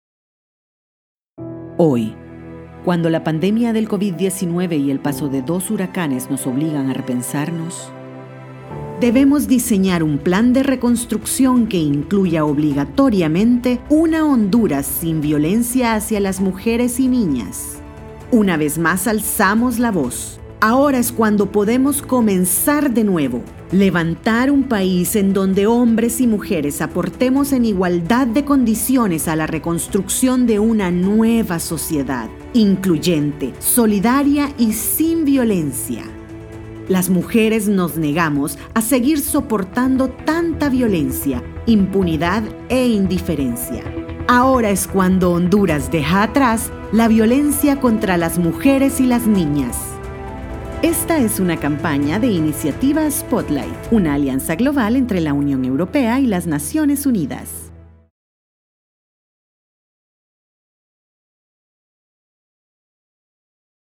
Native Spanish speaker, english voiceover, cheerful, bright, serious, convincing, conversational, corporate, educational, commercial spots.
Sprechprobe: Werbung (Muttersprache):